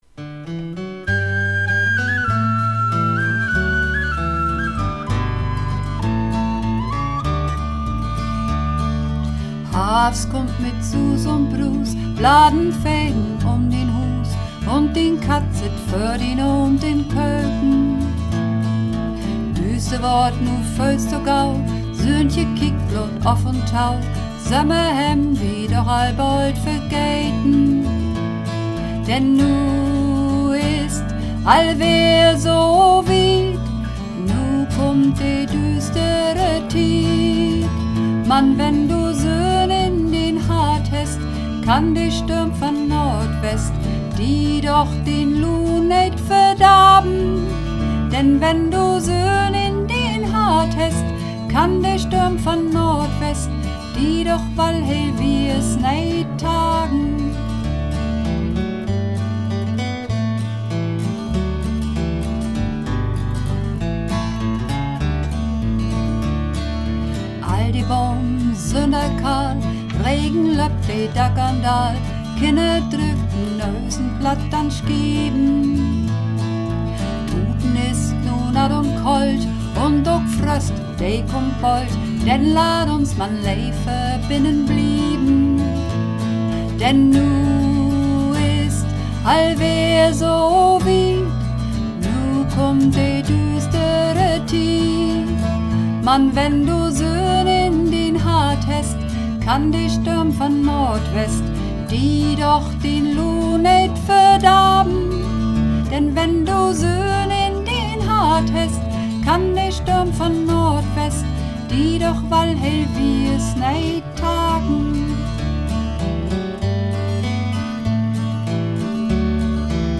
Runterladen (Mit rechter Maustaste anklicken, Menübefehl auswählen)   Harvst (Sopran und Alt - hoch)
Harvst__2_Sopran_Alt_hoch.mp3